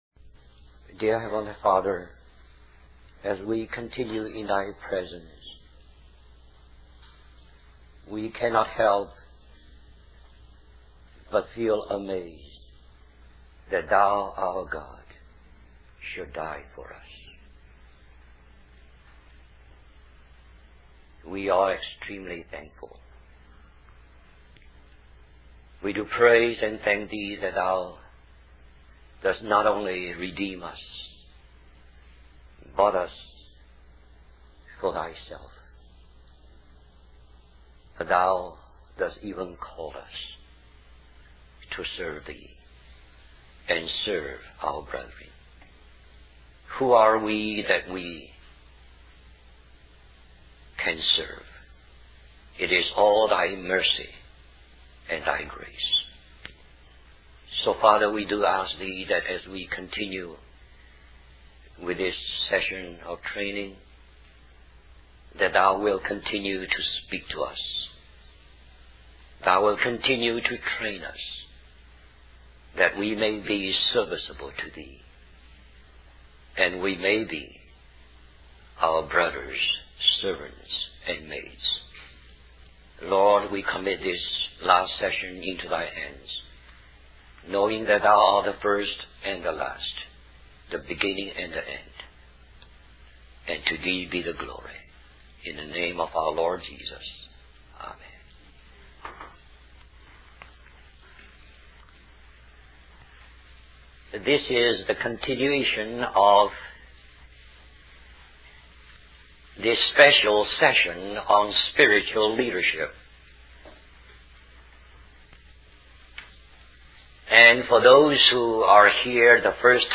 US Stream or download mp3 Summary This message was given at the leadership training retreat held in Richmond in 1987.